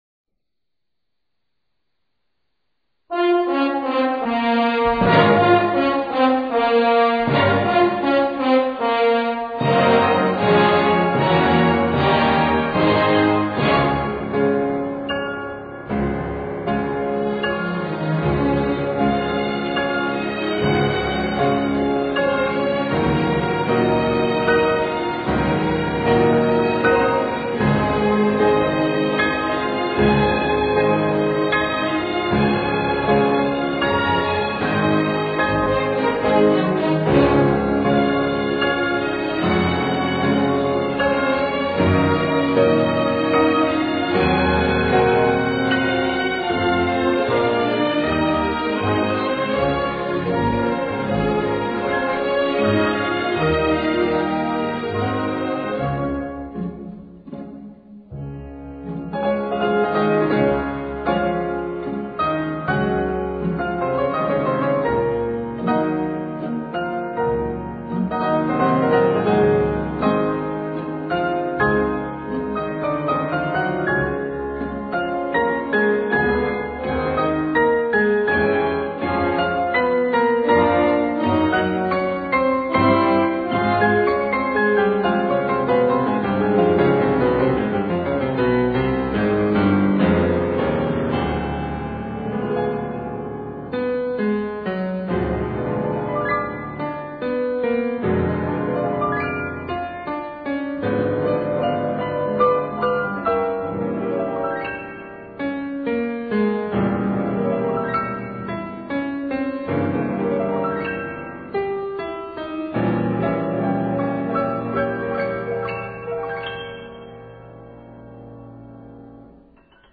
音樂類型：古典音樂
鋼琴音色燦爛而清晰，均衡的管弦樂聲部最為真實。